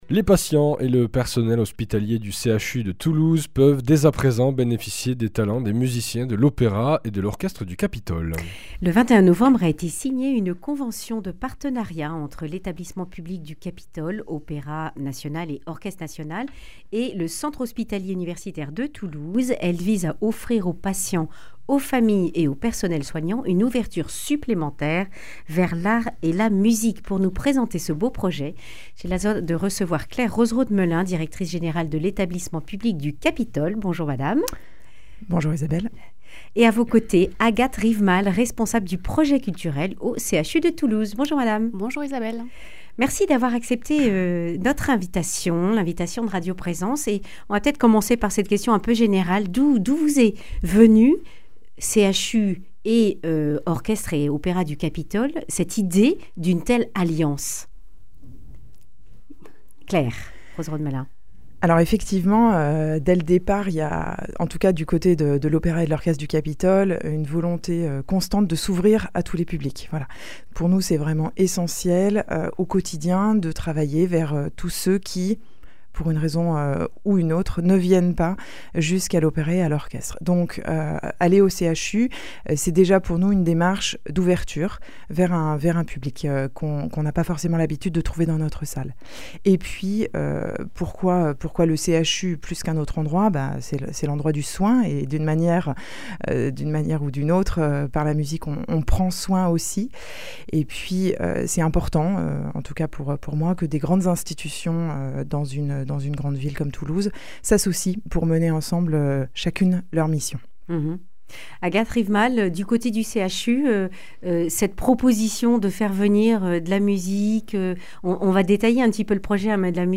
Accueil \ Emissions \ Information \ Régionale \ Le grand entretien \ Grâce à l’art, l’hôpital devient un lieu de vie plutôt qu’un lieu de (...)